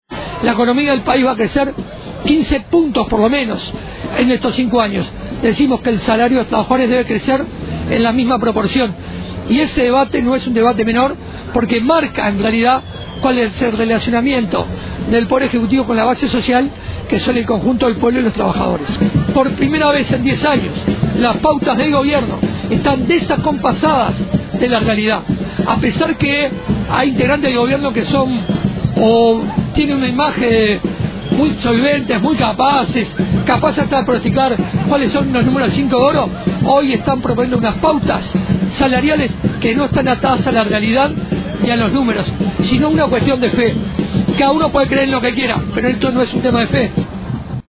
dijo en rueda de prensa que las pautas salariales del gobierno están fuera de la realidad.